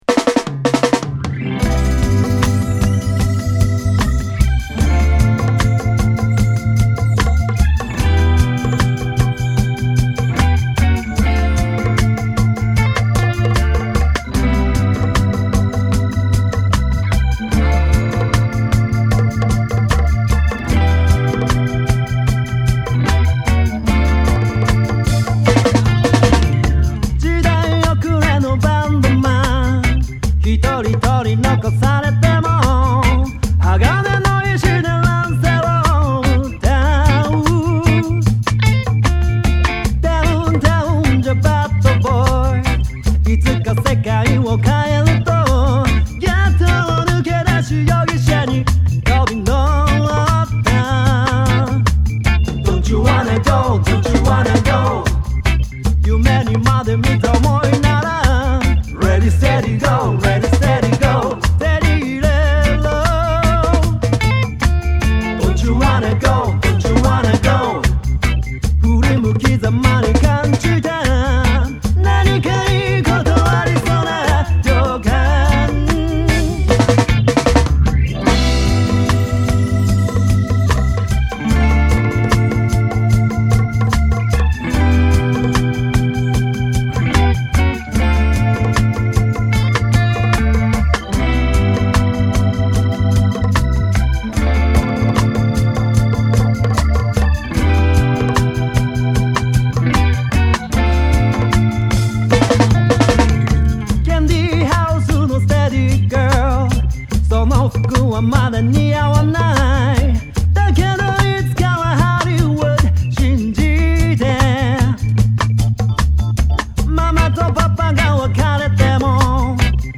スウィートなヴォーカルに力強い演奏の魅力も詰まった
(Original Version)